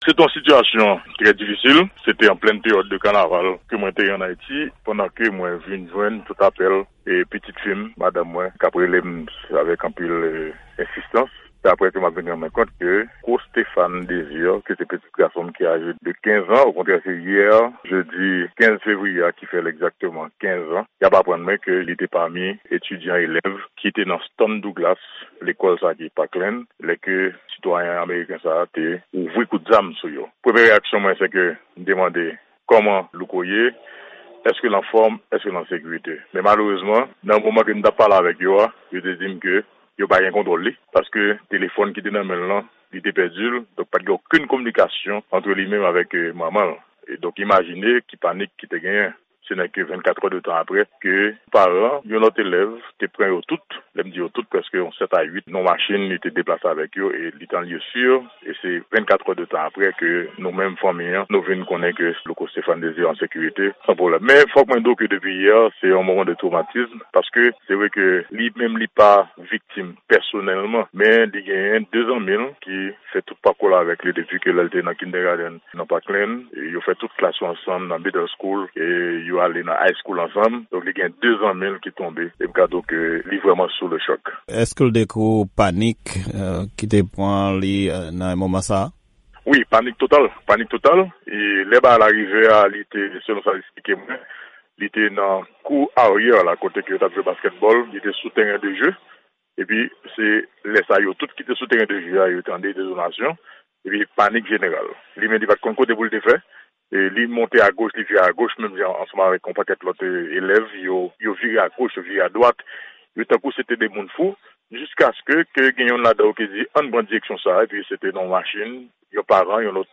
Temwayaj